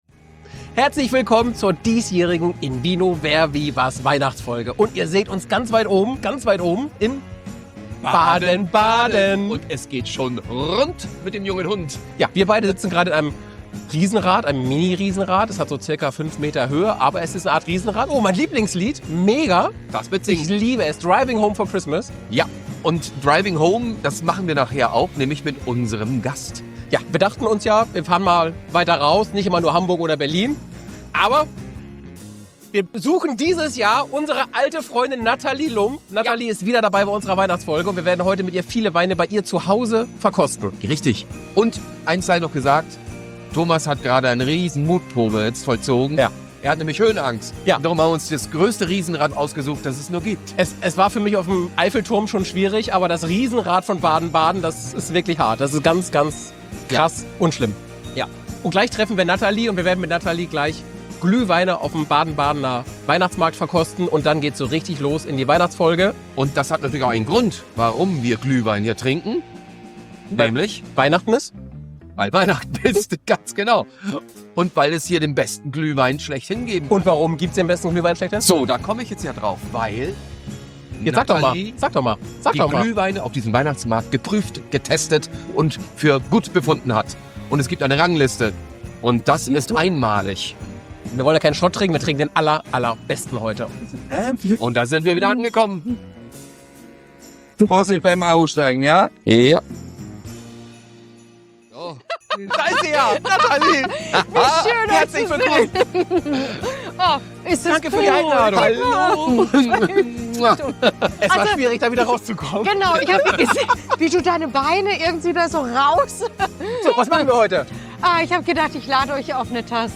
Dieses Jahr hat sich die komplette IN VINO-Familie auf den Weg nach Baden-Baden gemacht, um Weihnachten bei niemand Geringerem als Sommelière-Legende Natalie Lumpp zu feiern.
Wie es sich für eine richtige Weihnachtsfeier gehört, gibt es Geschenke, Spiele und jede Menge Familienatmosphäre.